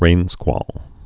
(rānskwôl)